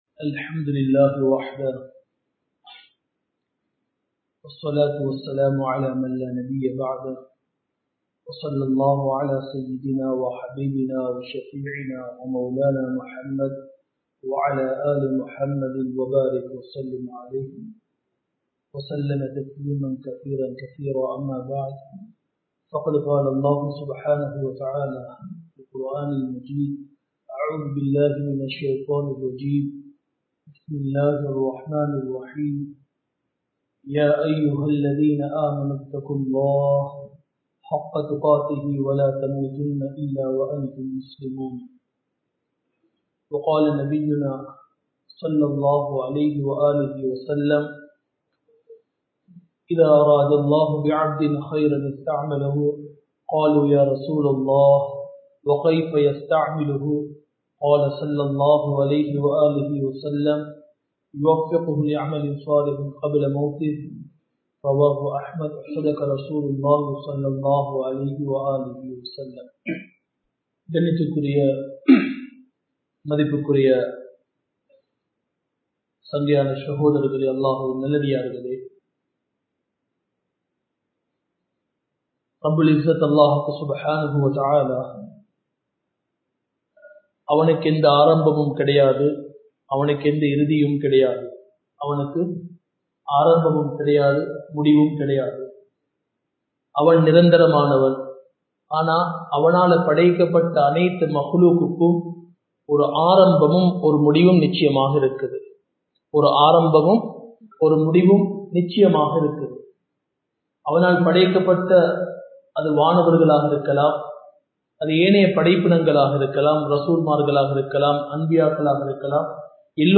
Iruthi Mudivai Anchuvoam (இறுதி முடிவை அஞ்சுவோம்) | Audio Bayans | All Ceylon Muslim Youth Community | Addalaichenai
Muhideen (Markaz) Jumua Masjith